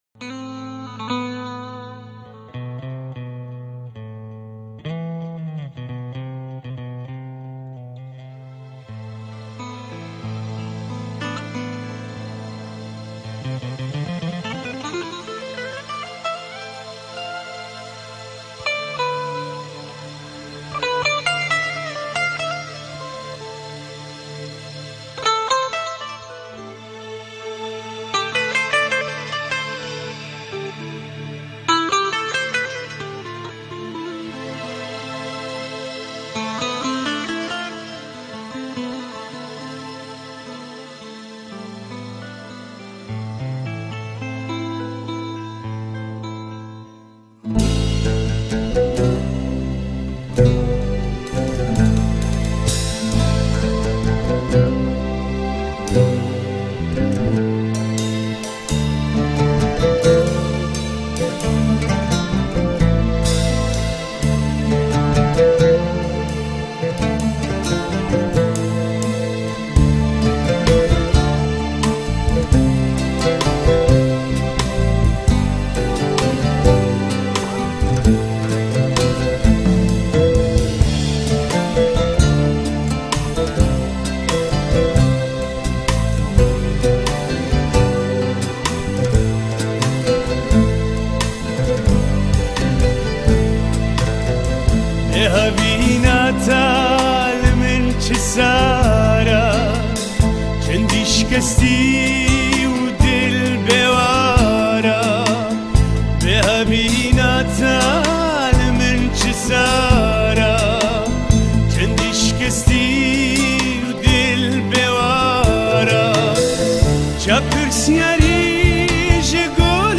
گورانی کوردی